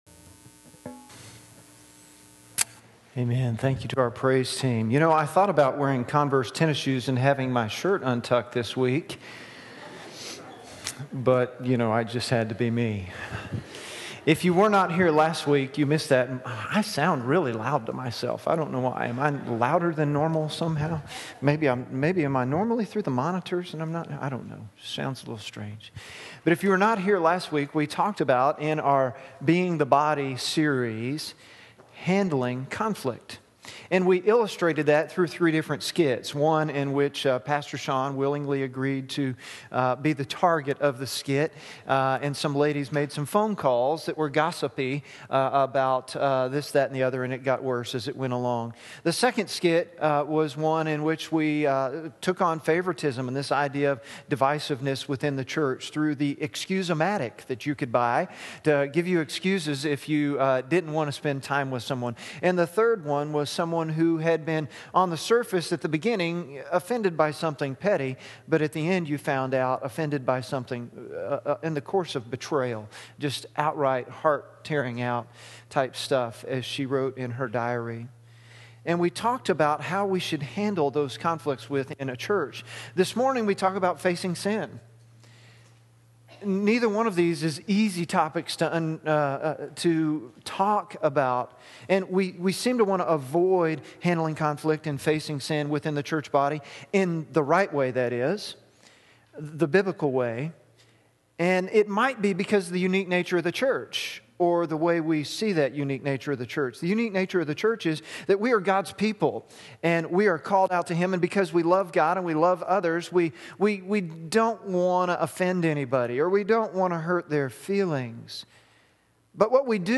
Various Scriptures Sermon notes on YouVersion Being the Body: Facing Sin